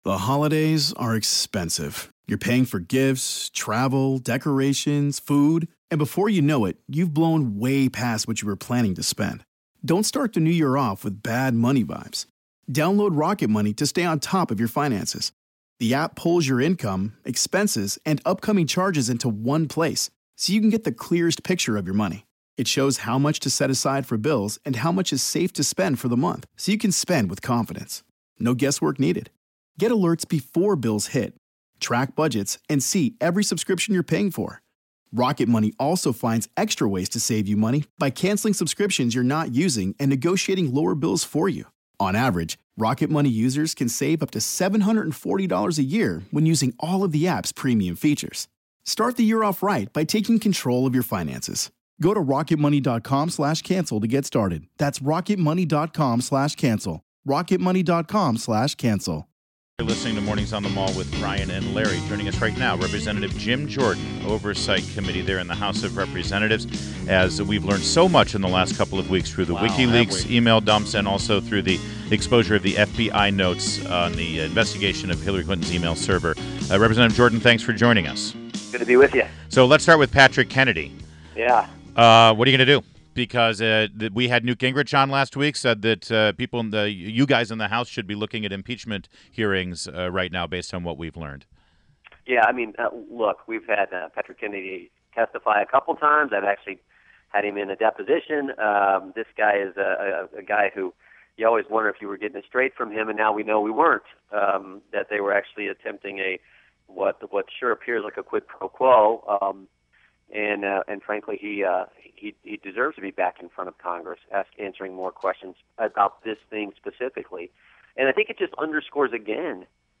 INTERVIEW – REP. JIM JORDAN – R-Ohio and Member of House Oversight Committee